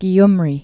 (gē-mrē, gym-rē) or Ku·may·ri (k-mārē, -mā-rē)